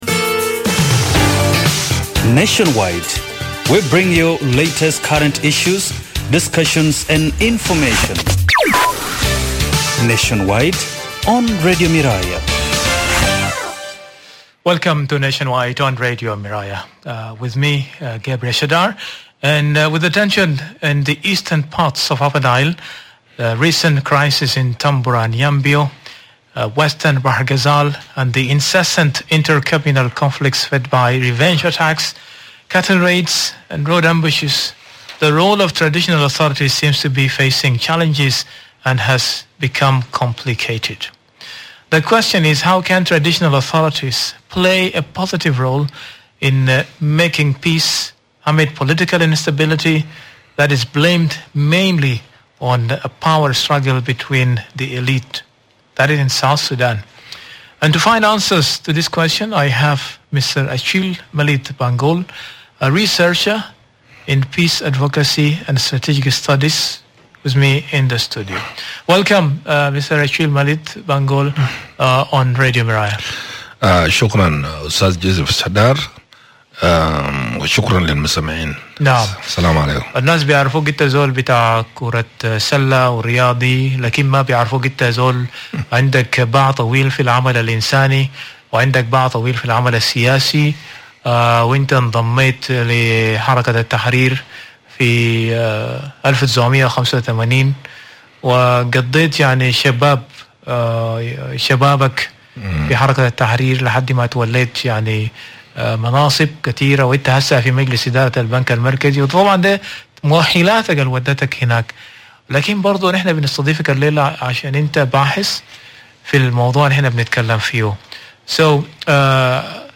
a researcher in Peace, Advocacy and Strategic Studies.